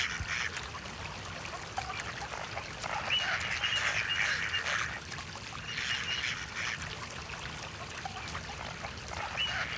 Duck Landing
duck-landing-2-sounds.wav